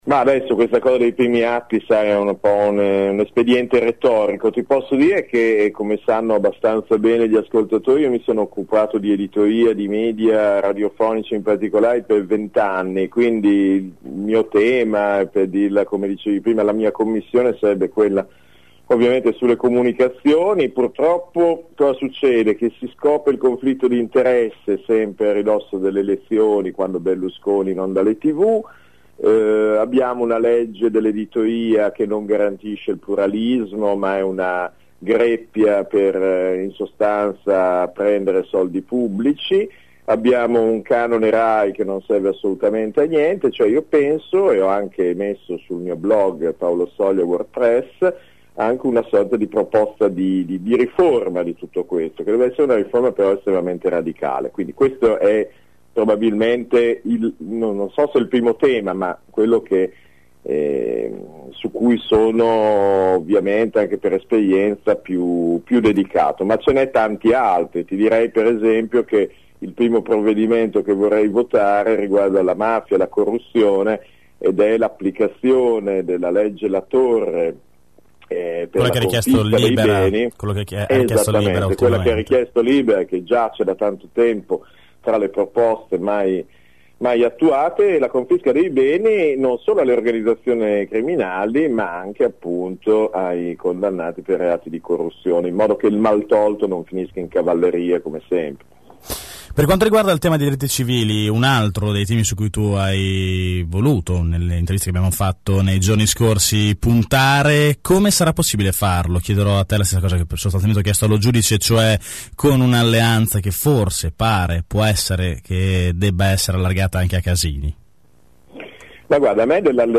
Venerdì mattina durante AngoloB abbiamo organizzato una tavola rotonda con alcuni dei candidati.
Per Sel abbiamo intervistato: